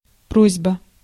Ääntäminen
Synonyymit program software industry job application application form Ääntäminen UK : IPA : [ˌæ.plə.ˈkeɪ.ʃən] US : IPA : [ˌæ.plə.ˈkeɪ.ʃən] Lyhenteet ja supistumat Appl.